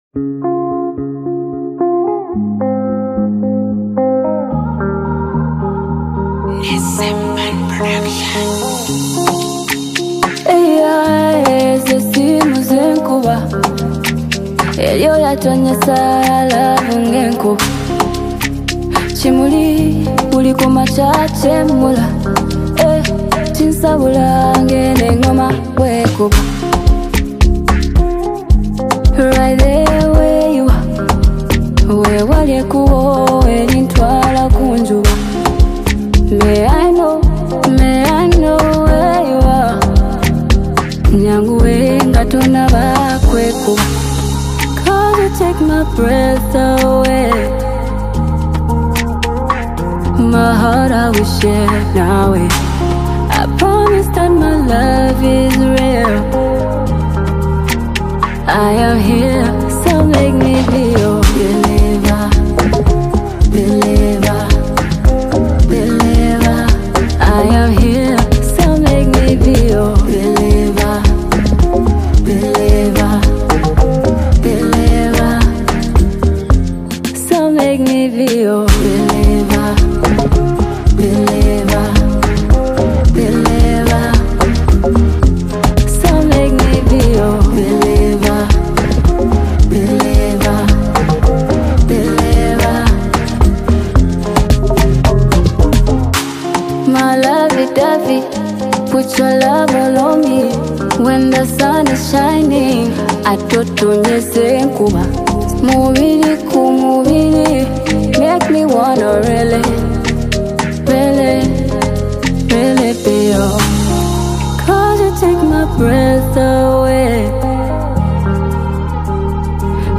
With a gentle but confident voice